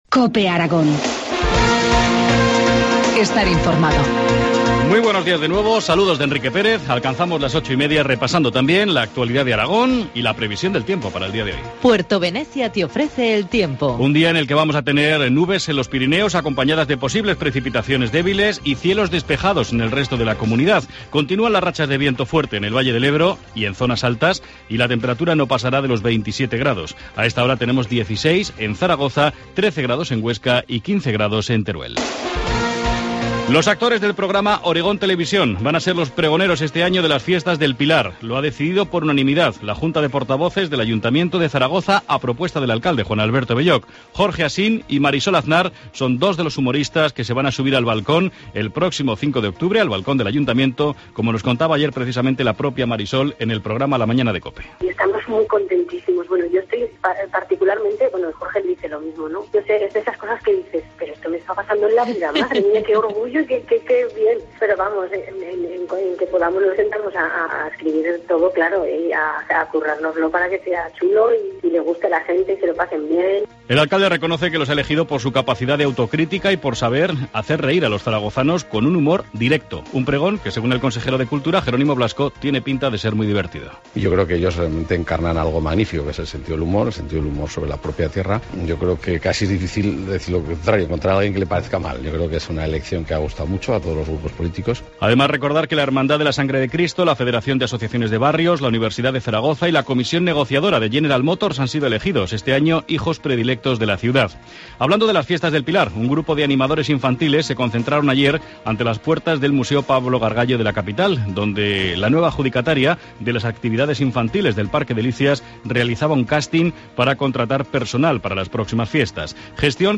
Informativo matinal, miércoles 18 de septiembre, 8.25 horas